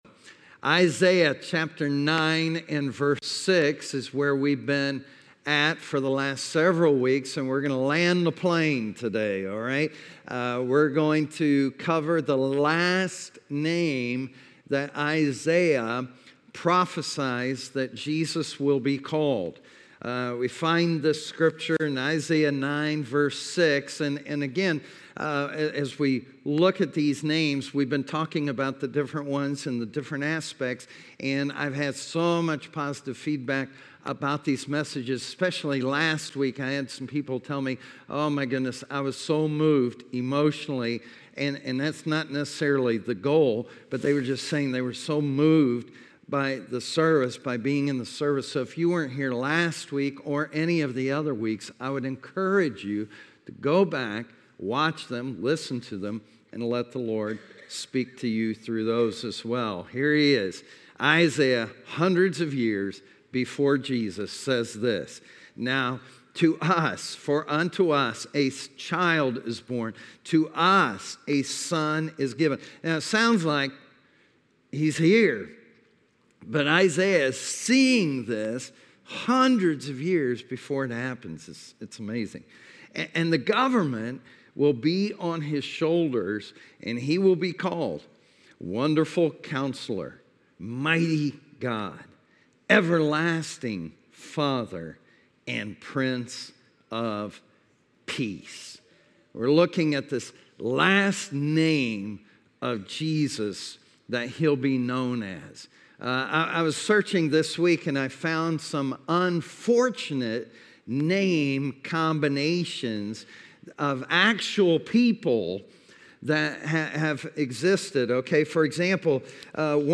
A message from the series "Who Jesus Is."